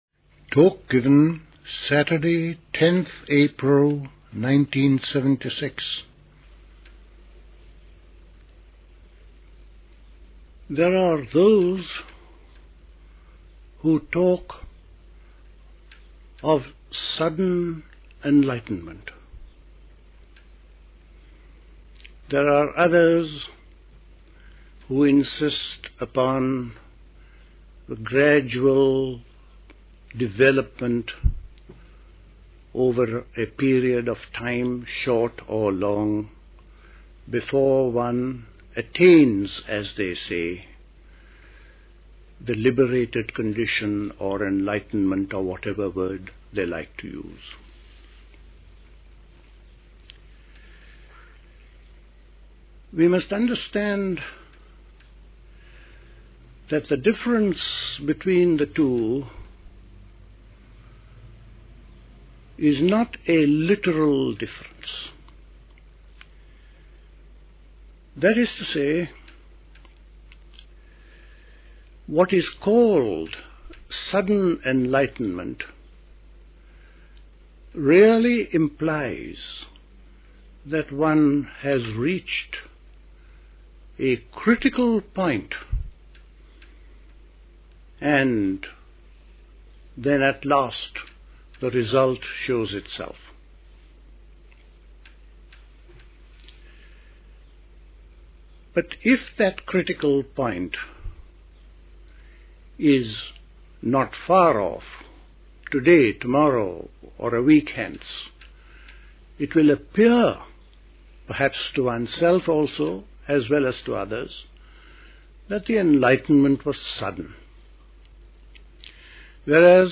A talk
at Dilkusha, Forest Hill, London on 10th April 1976